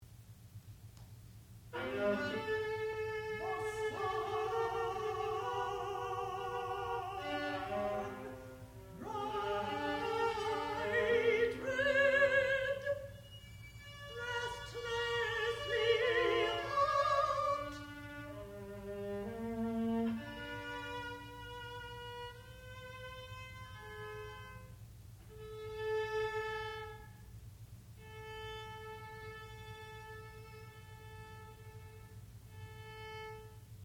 sound recording-musical
classical music
mezzo-soprano
viola